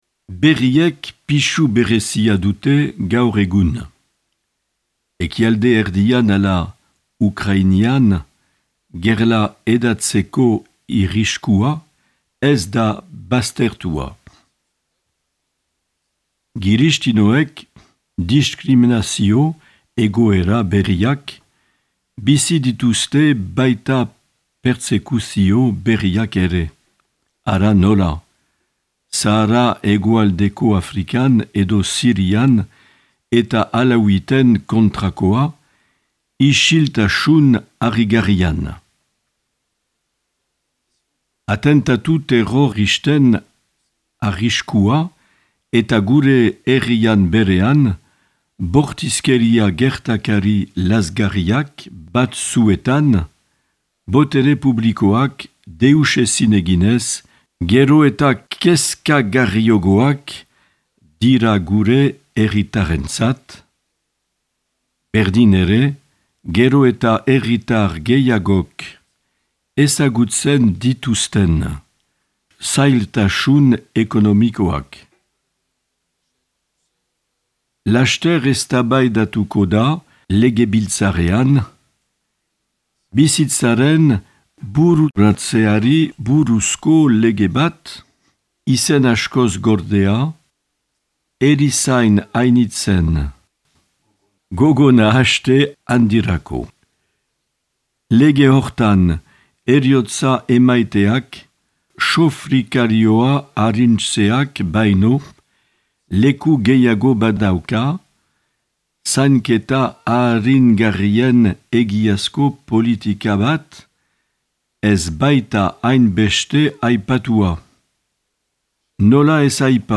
Marc Aillet jaun apezpikua.